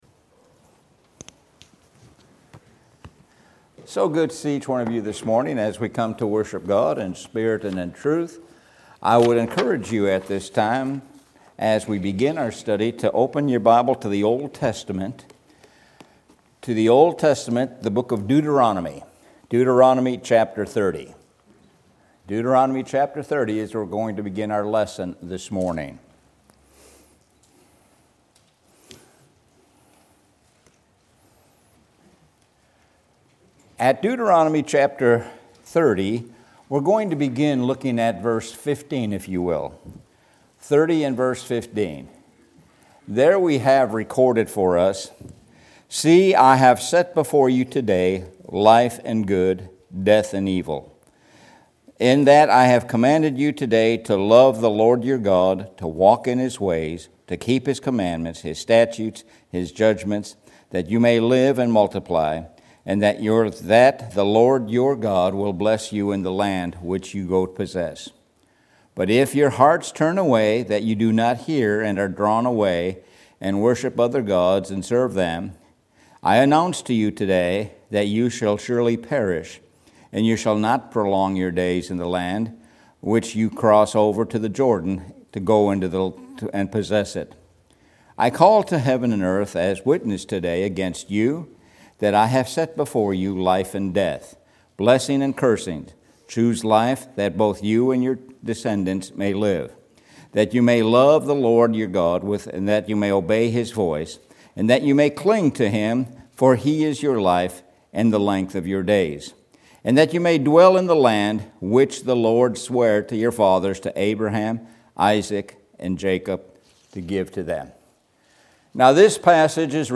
Sun AM Sermon- Choose Life